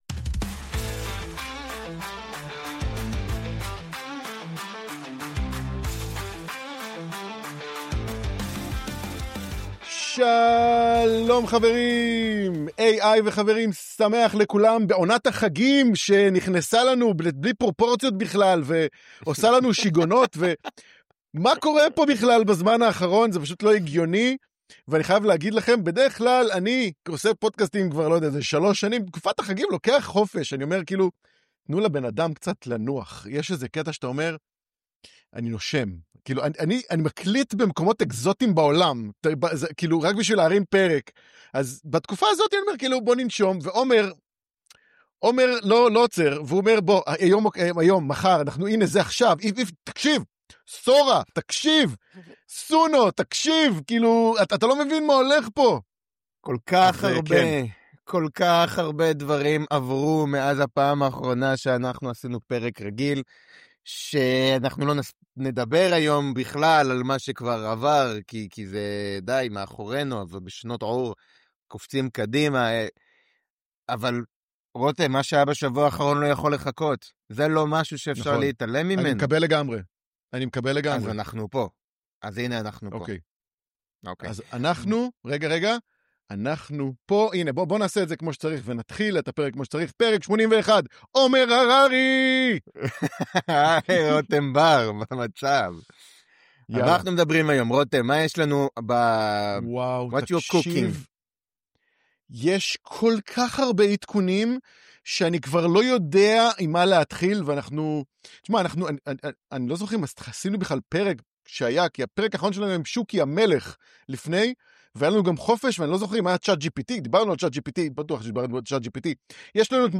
רוצים להבין איך בינה מלאכותית (AI) משנה את חיינו? בכל פרק, שני מומחים בתחום AI